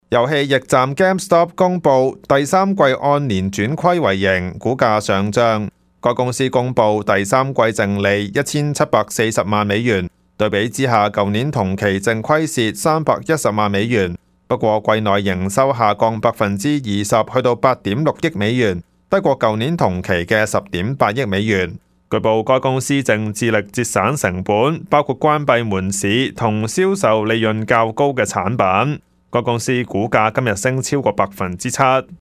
news_clip_21678.mp3